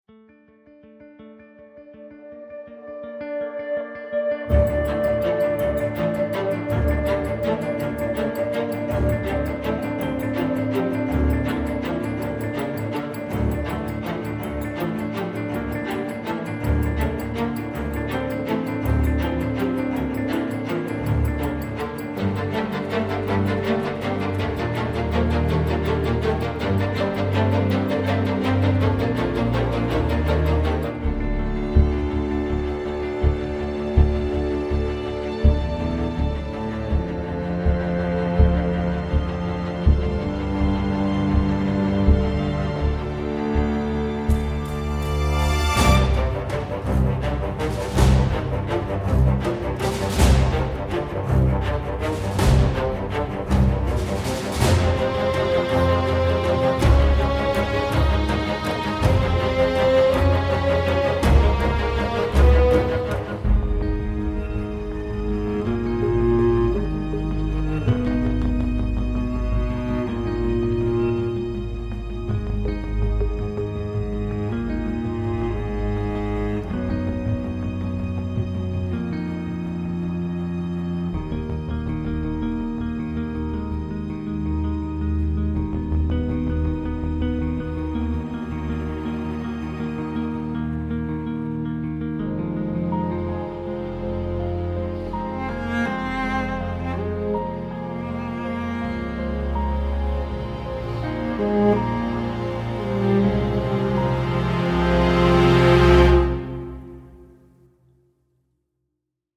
violoncelle
Efficace et parfois d’une beauté insulaire.